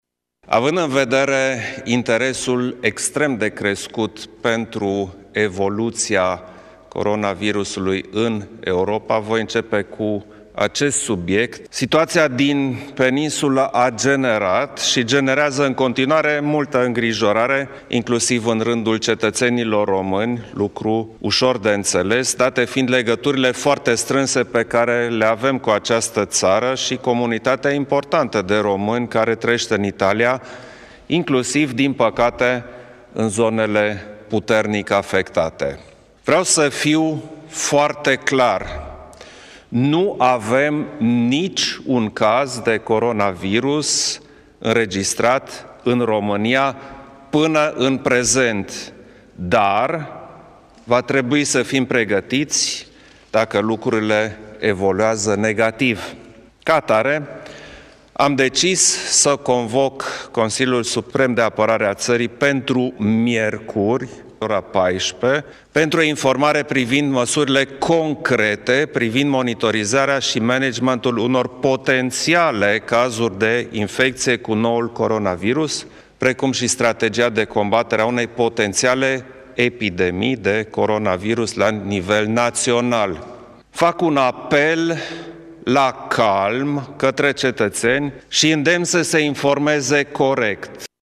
Președintele și-a început declarația de presă cu problema coronavirusului. Klaus Iohannis a reconfirmat că în acest moment nu avem niciun caz de coronavirus în Romania, dar trebuie să fim pregătiți în cazul în care situația evoluează negativ.